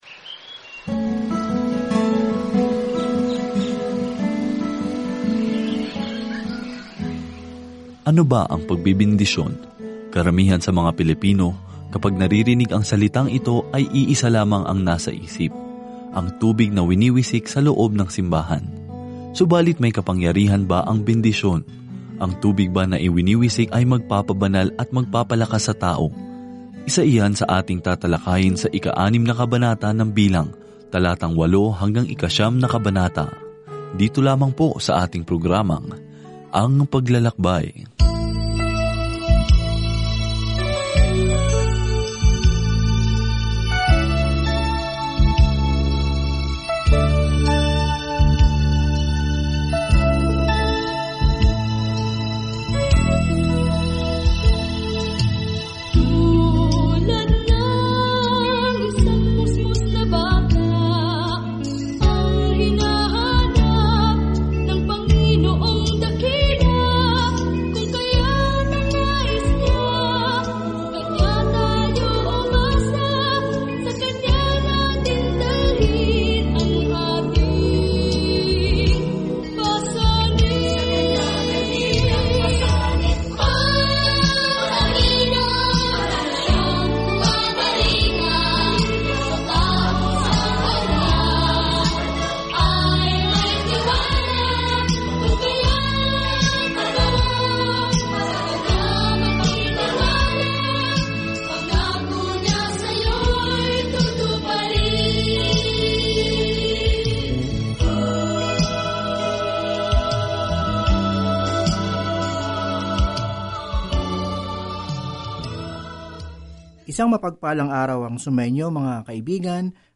Banal na Kasulatan Mga Bilang 6:8-27 Mga Bilang 7 Araw 4 Umpisahan ang Gabay na Ito Araw 6 Tungkol sa Gabay na ito Sa aklat ng Mga Bilang, tayo ay naglalakad, gumagala, at sumasamba kasama ng Israel sa loob ng 40 taon sa ilang. Araw-araw na paglalakbay sa Numero habang nakikinig ka sa audio study at nagbabasa ng mga piling talata mula sa salita ng Diyos.